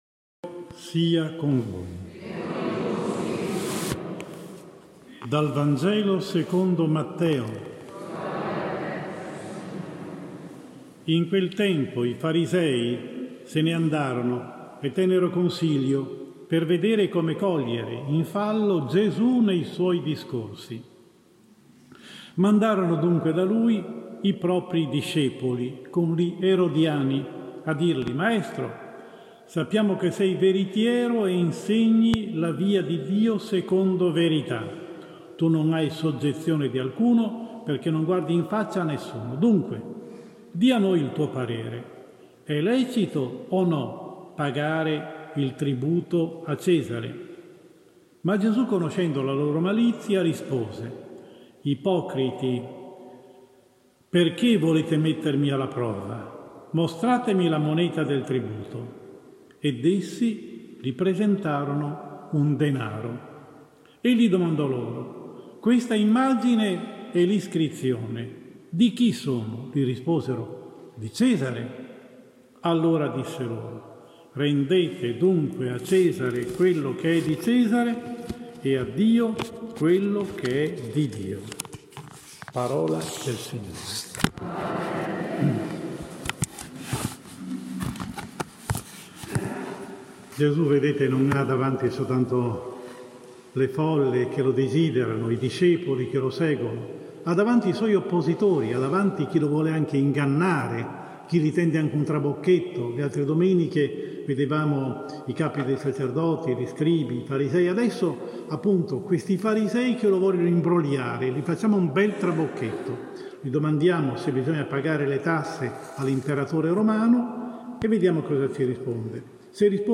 18 ottobre 2020 – Domenica XXIX anno A – GIORNATA MISSIONARIA: omelia